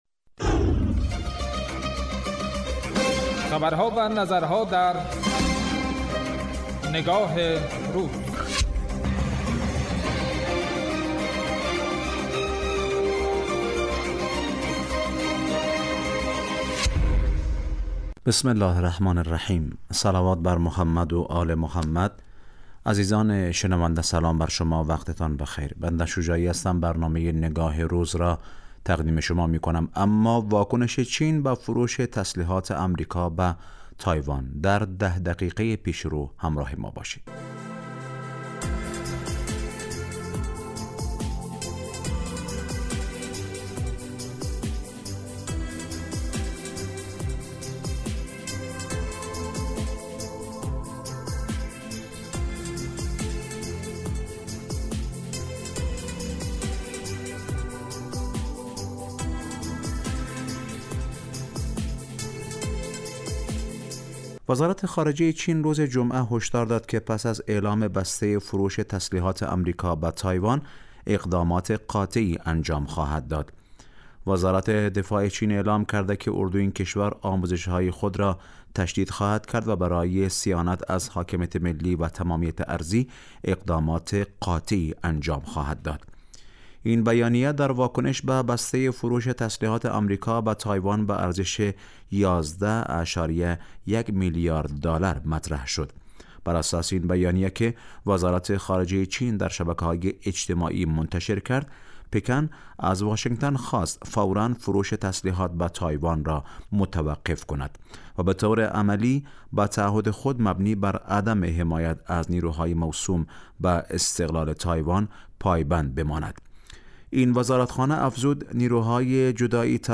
برنامه تحلیلی نگاه روز ازشنبه تا پنجشنبه راس ساعت 14 به مدت 10 دقیقه پخش می گردد